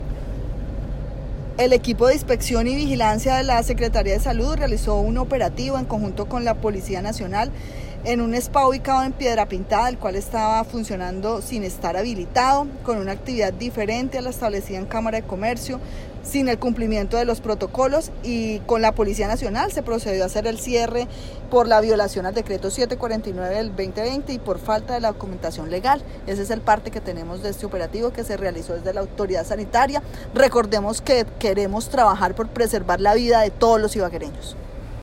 Johana Aranda, secretaria de Salud, indicó que el establecimiento se encontraba abierto al público, ejerciendo una actividad económica diferente a la establecida en Cámara de Comercio, lo que conllevó al cierre inmediato por falta de la documentación legal.